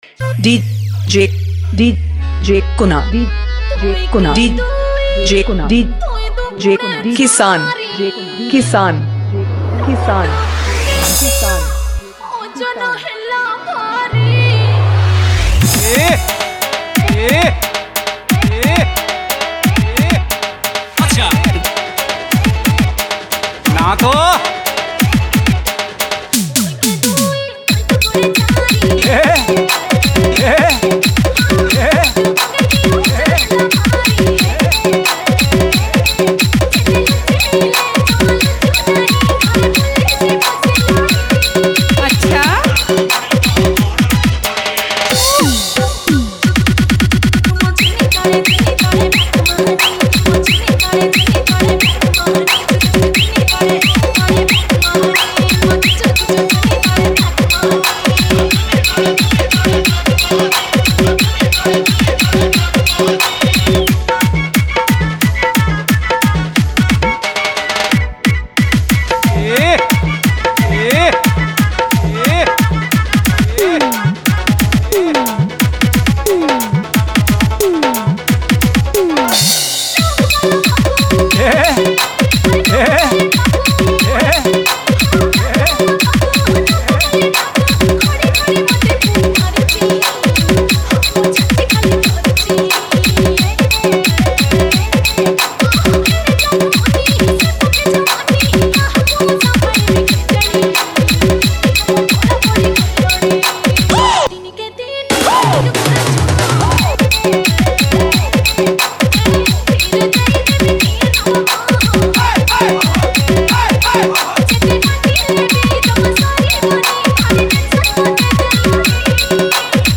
• Category:Odia New Dj Song 2017